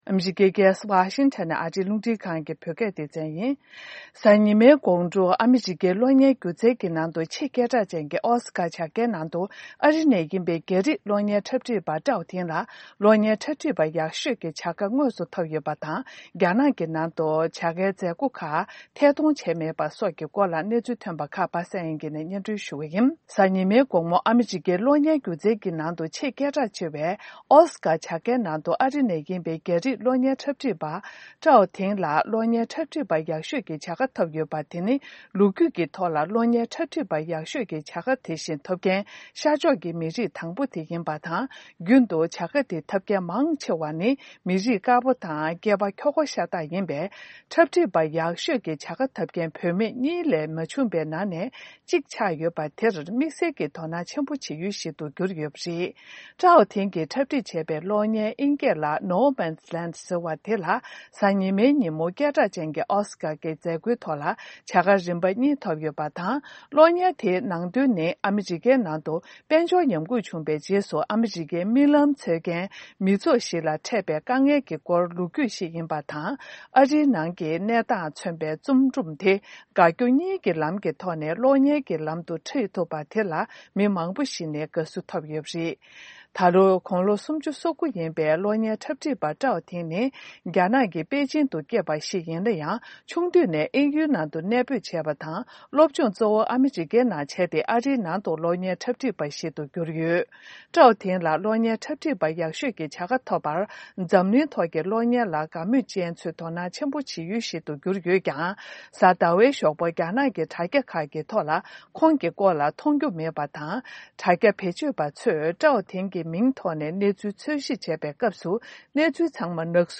སྙན་སྒྲོན་ཞུ་ཡི་རེད།